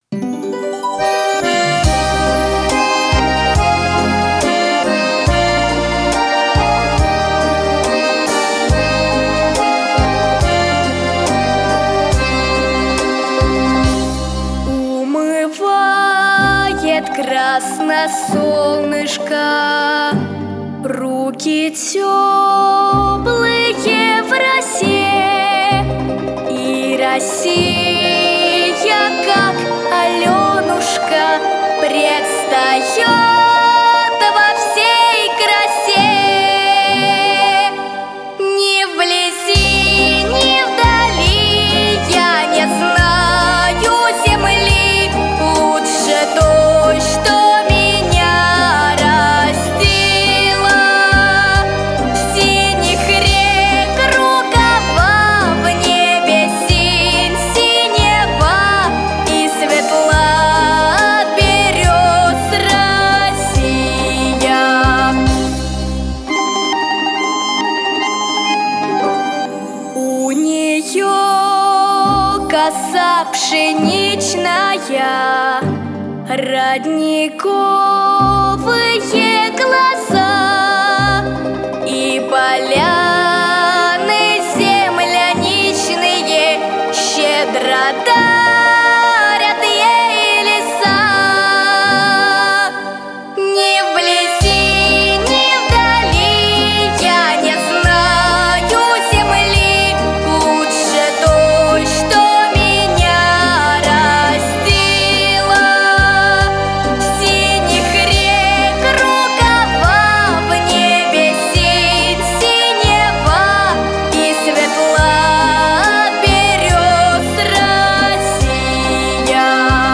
• Качество: Хорошее
• Категория: Детские песни
патриотическая